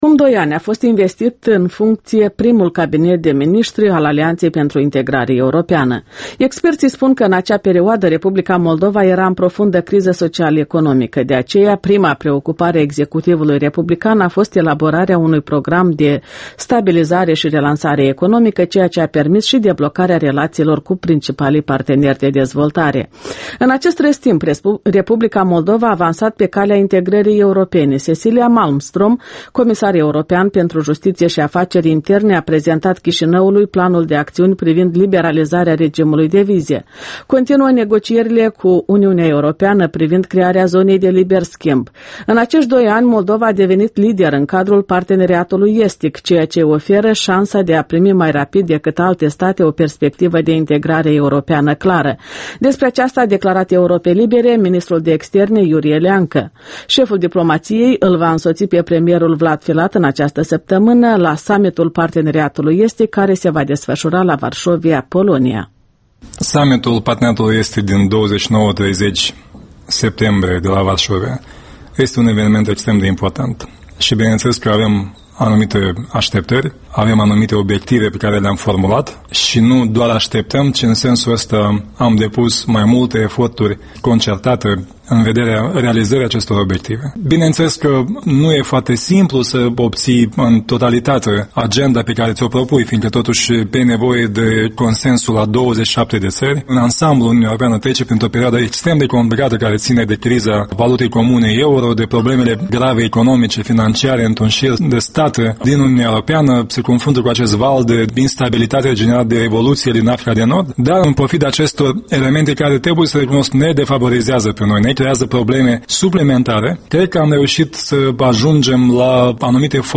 Interviul dimineții la Europa Liberă: Iurie Leancă despre prezentul și viitorul integrării europene